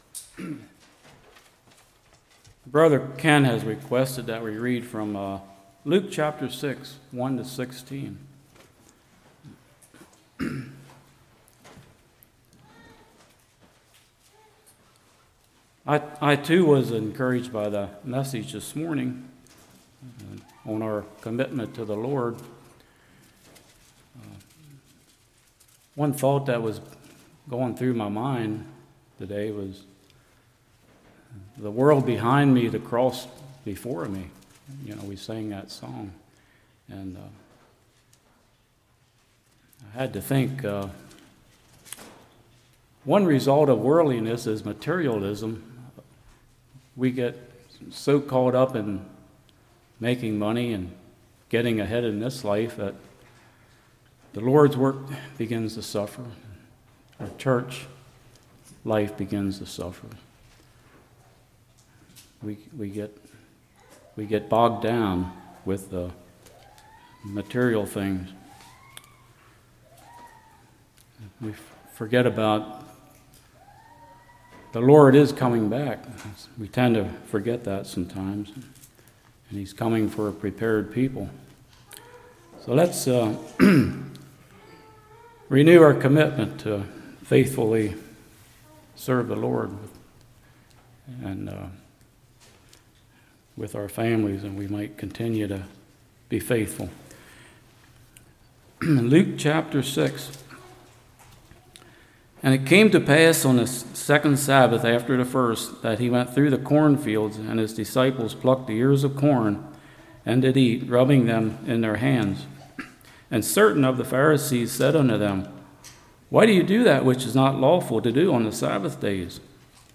Service Type: Evening